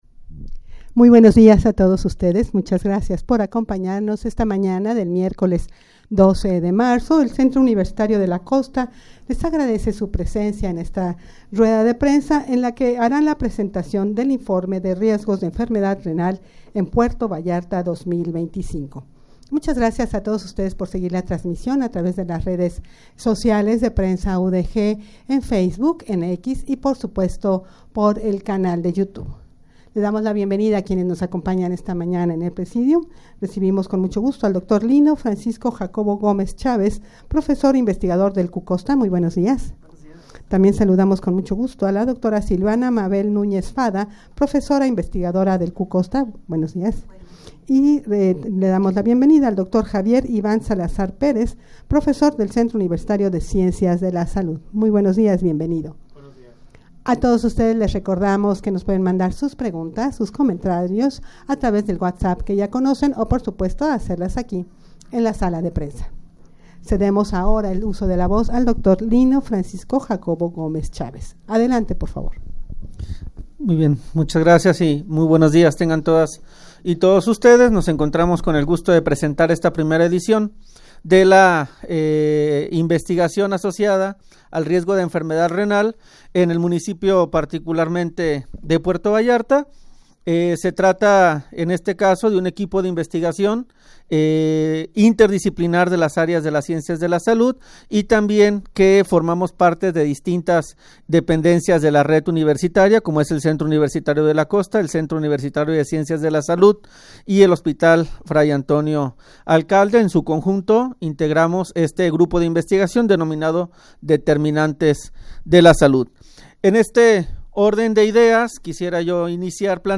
Audio de la Rueda de Prensa
rueda-de-prensa-presentacion-de-informe-de-riesgos-de-enfermedad-renal-en-puerto-vallarta-2025.mp3